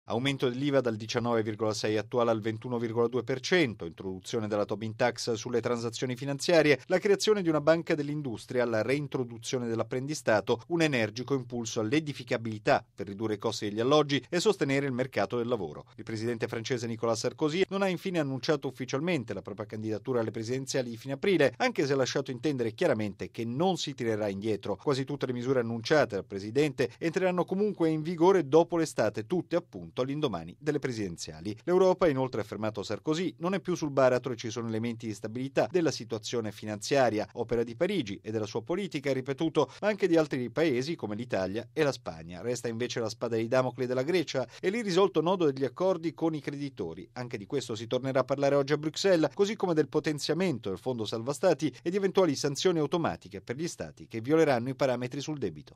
Il capo dell’Eliseo si è soffermato in particolare sulle misure per favorire l’occupazione e sull’aumento dell’Iva in Francia. Il servizio